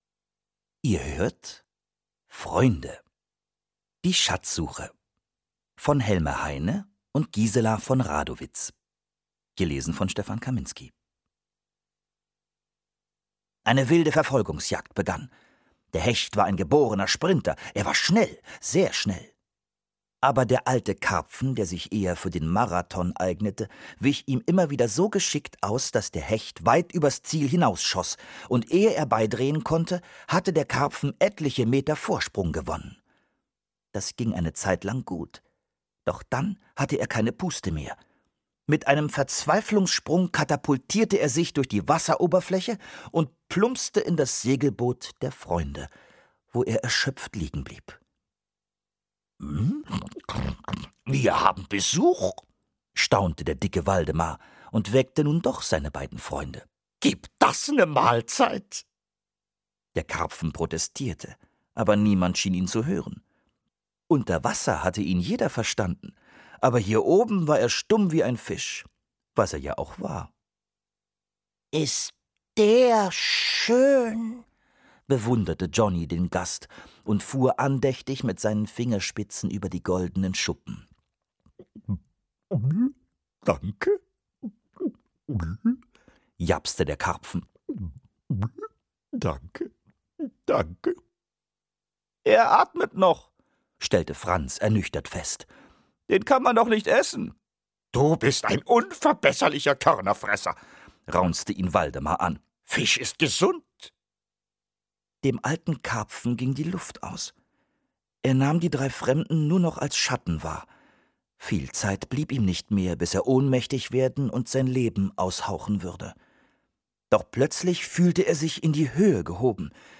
Schlagworte Abenteuer • Franz • Hörbuch; Lesung für Kinder/Jugendliche • JOHNNY • Schatz • Waldemar